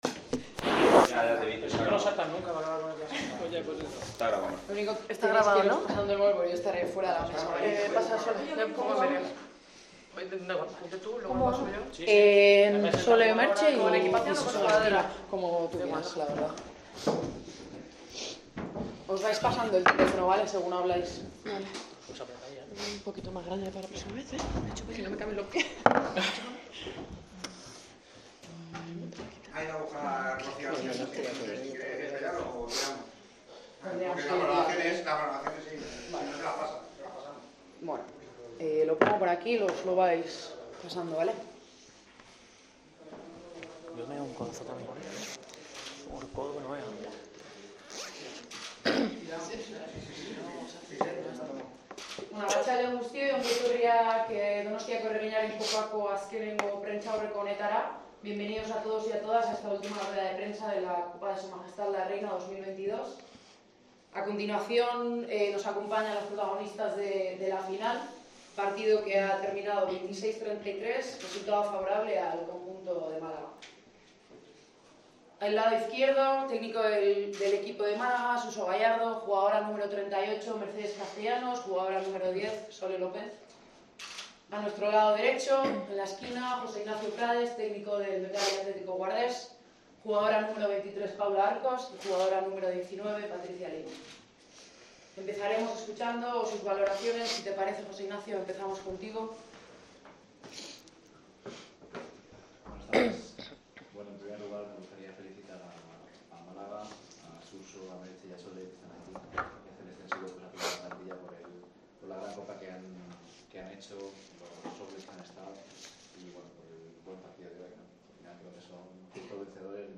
Las panteras consiguieron su segunda Copa de la Reina en 3 años, y expresaron su felicidad ante los micrófonos tras el término del encuentro.